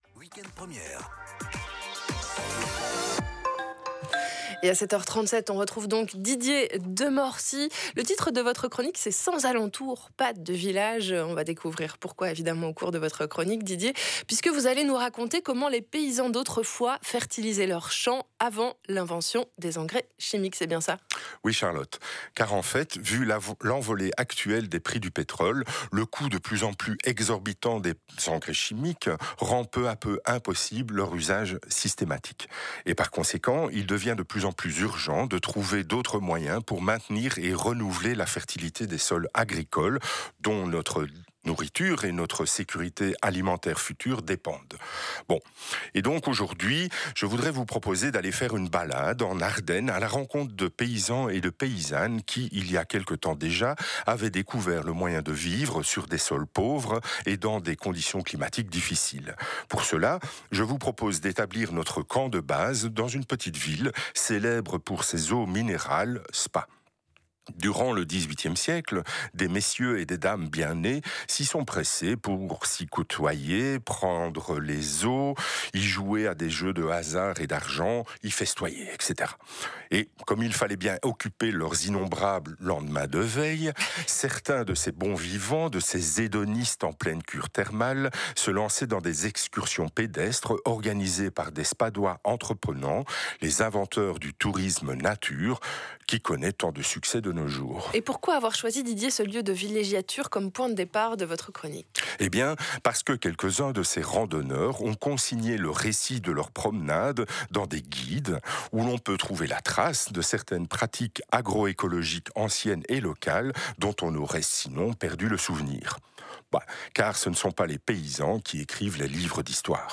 La chronique :